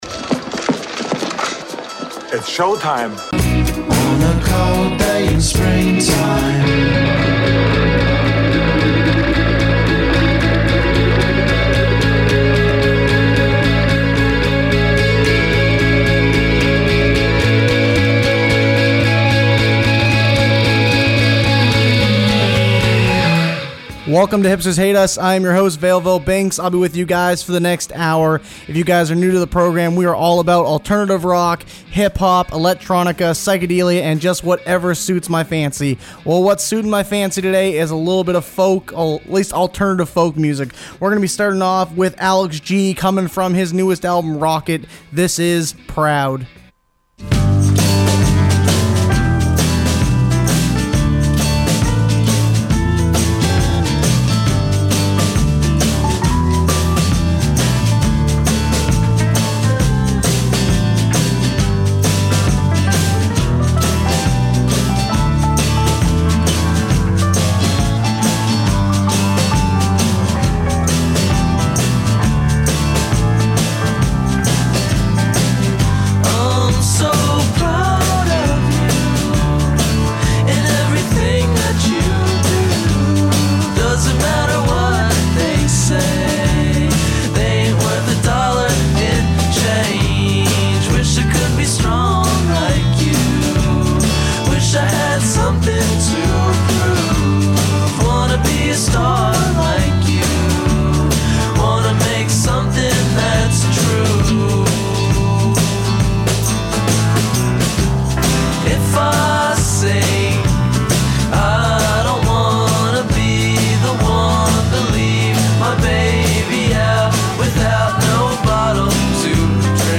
An Open Format Music Show. Britpop, Electronica, Hip-Hop, Alternative Rock, and Canadian music